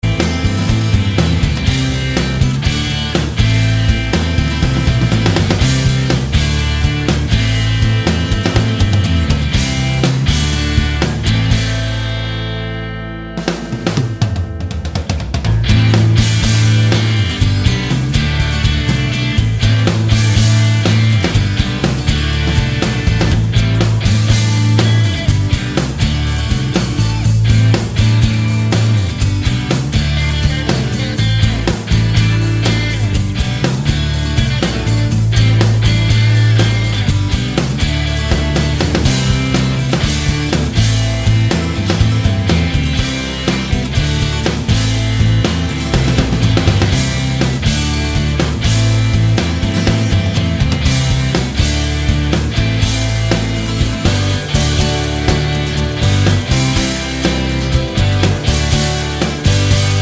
no Backing Vocals Indie / Alternative 4:24 Buy £1.50